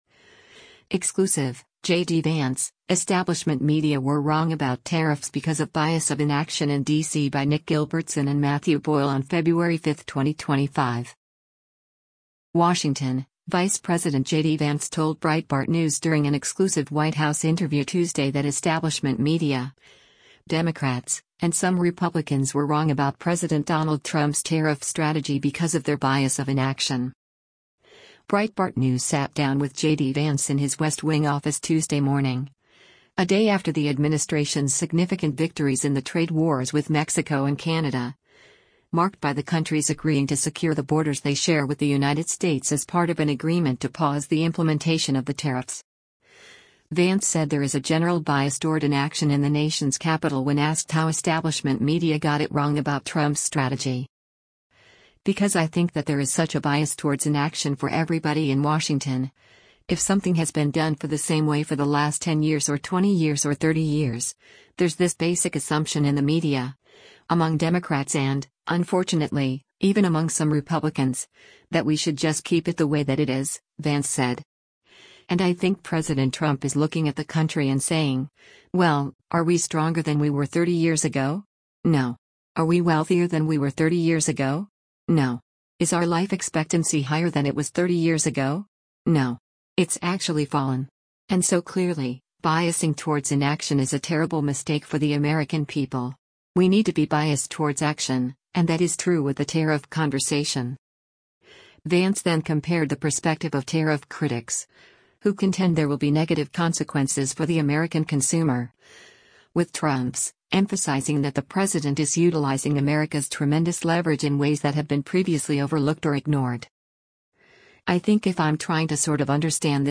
Breitbart News sat down with JD Vance in his West Wing office Tuesday morning, a day after the administration’s significant victories in the trade wars with Mexico and Canada, marked by the countries agreeing to secure the borders they share with the United States as part of an agreement to pause the implementation of the tariffs.